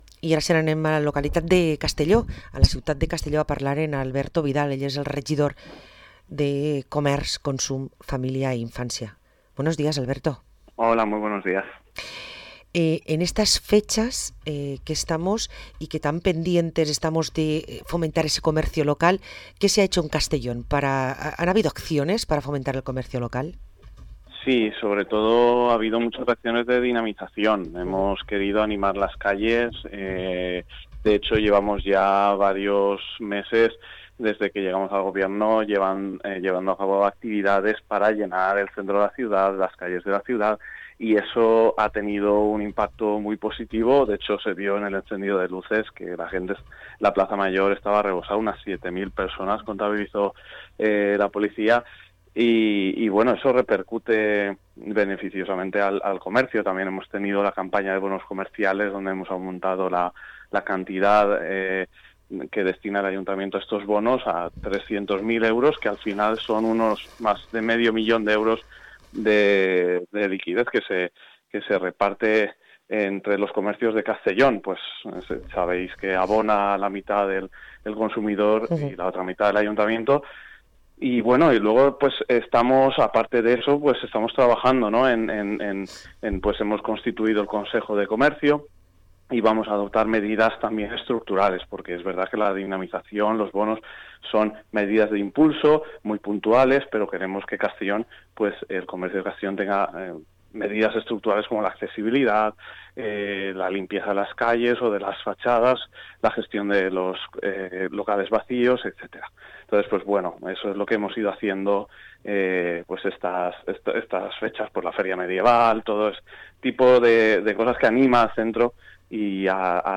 Parlem amb Alberto Vidal, regidor de comerç a l´Ajuntament de Castelló